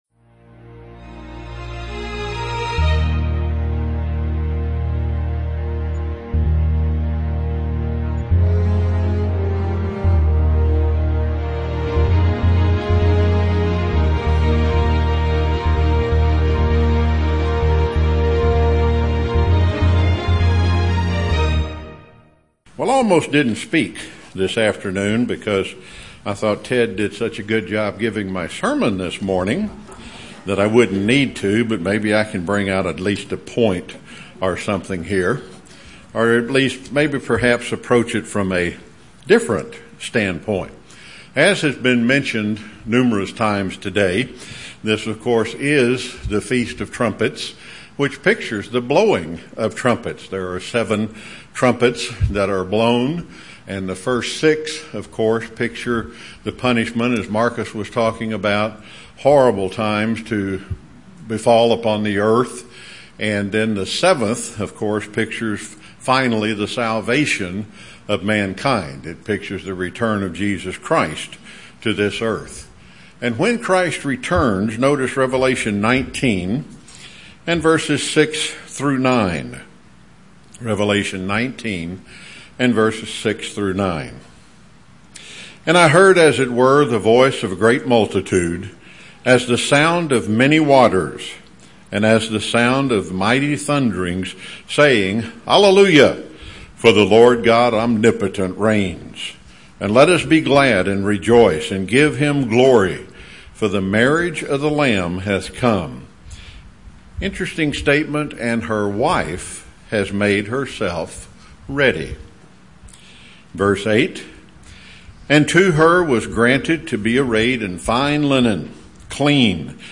You are celebrating great feast of trumpets.